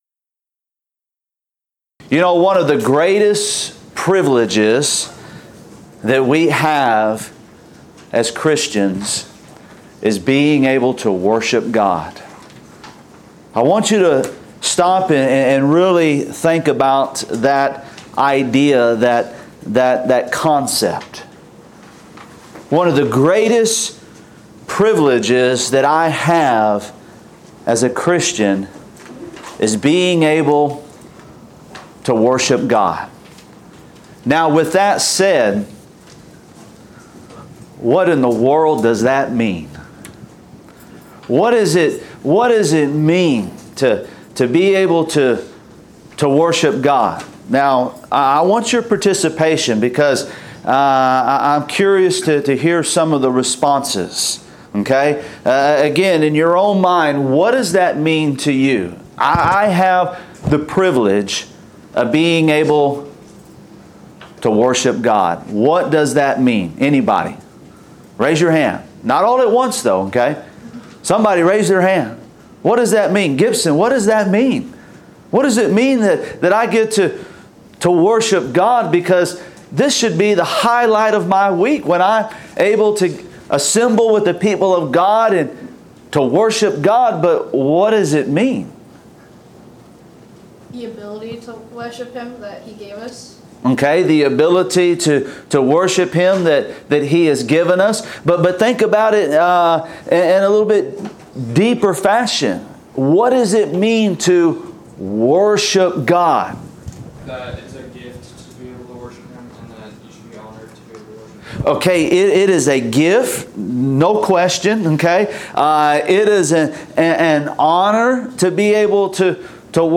Event: Discipleship U 2016
lecture